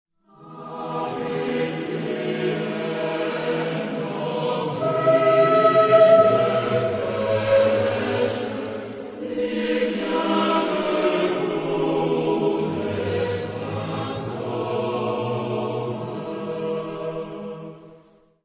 Genre-Style-Forme : Sacré ; Chant de Noël ; Populaire
Type de choeur : SATB  (4 voix mixtes )
Instruments : Orgue (1)
Tonalité : mi majeur
Sources musicologiques : French traditional carol